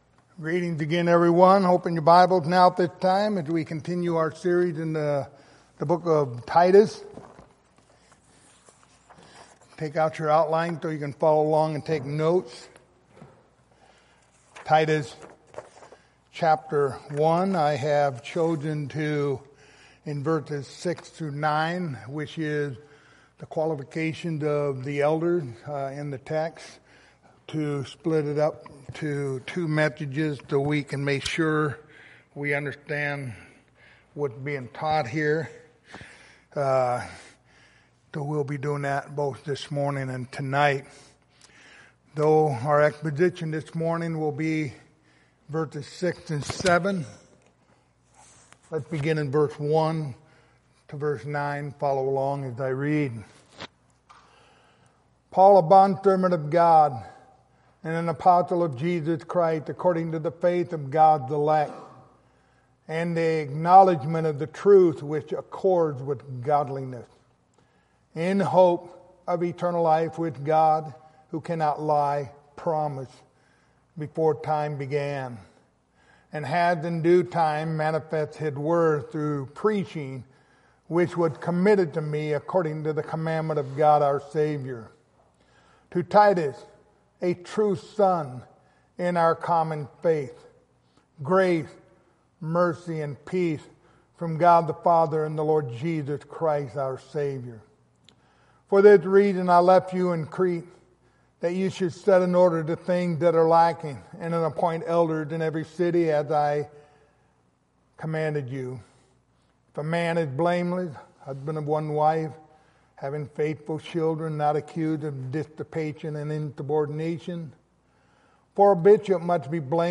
Passage: Titus 1:6-9 Service Type: Sunday Morning Topics